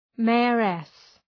Shkrimi fonetik {‘meærıs}